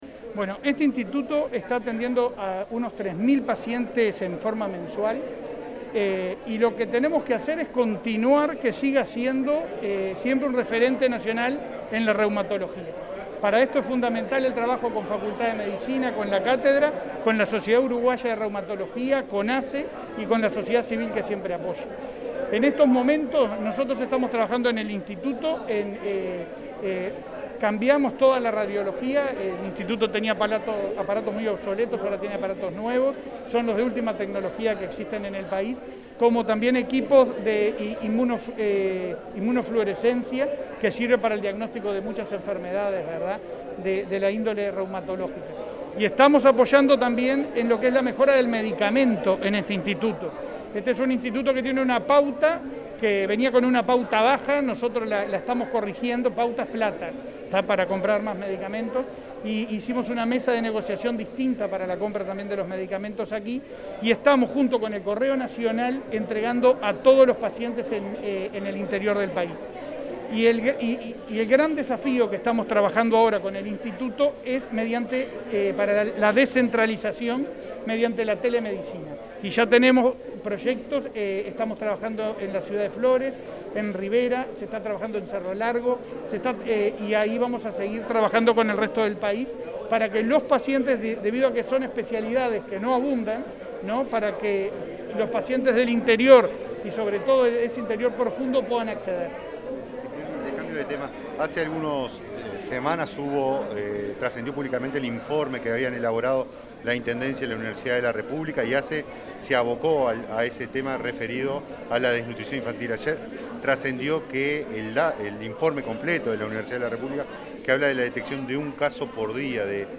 Declaraciones a la prensa del titular de ASSE, Leonardo Cipriani
Declaraciones a la prensa del titular de ASSE, Leonardo Cipriani 11/11/2021 Compartir Facebook X Copiar enlace WhatsApp LinkedIn El presidente de ASSE, Leonardo Cipriani, participó de la celebración por los 50 años del Instituto Nacional de Reumatología y, luego, dialogó con la prensa.